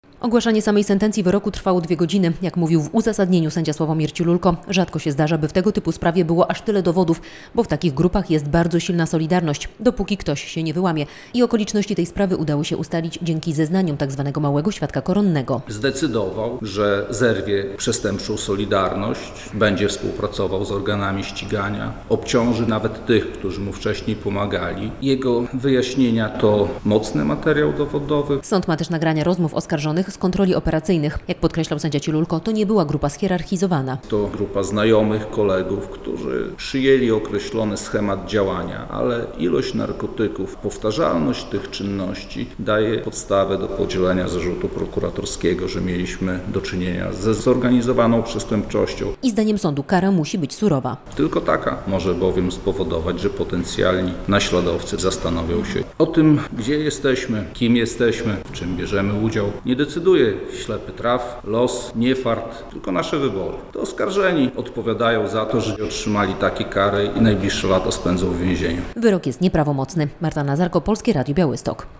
Wyroki skazujące ws. obrotu narkotykami na dużą skalę - relacja